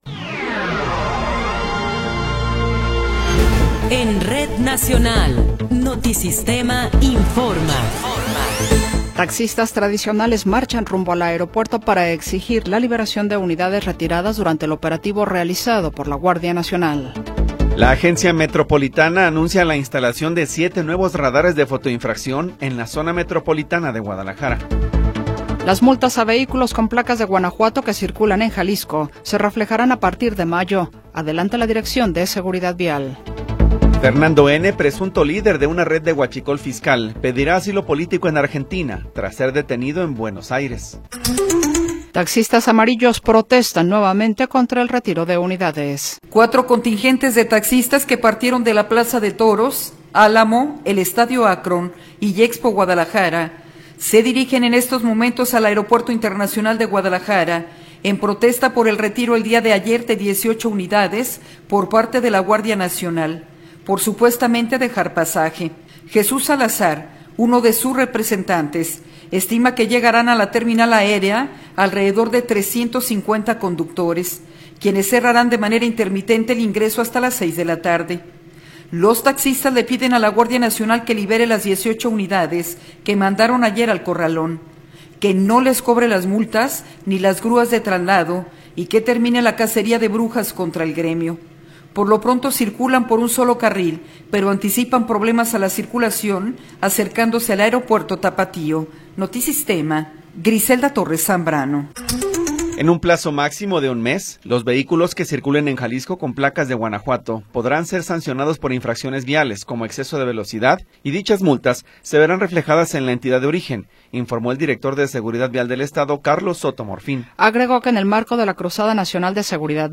Noticiero 14 hrs. – 24 de Abril de 2026
Resumen informativo Notisistema, la mejor y más completa información cada hora en la hora.